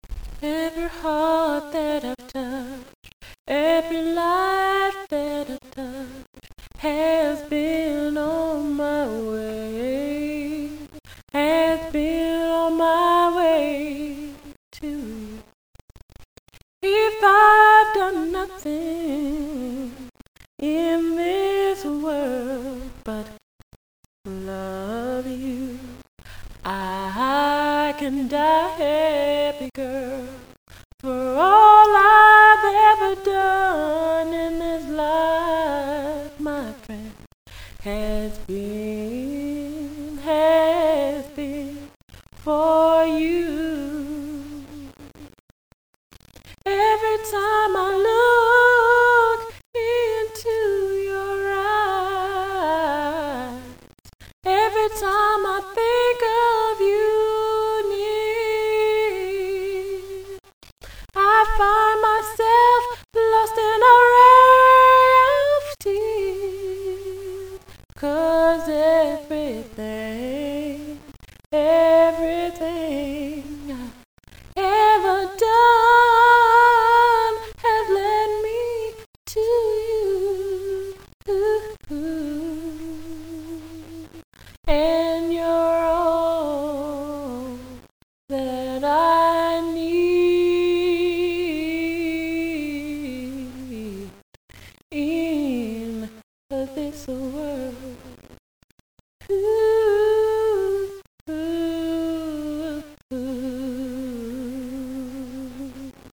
I hope that you enjoy the music here all of which is sang without background music mostly intended for those that have insisted that I create this page so that they can hear me sing a bit more.